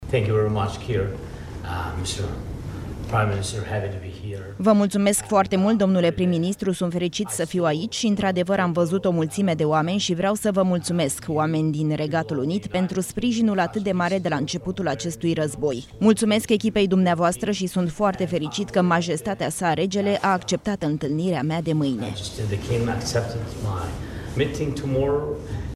02mar-09-Zelenski-vreau-sa-va-multumesc-TRADUS-1.mp3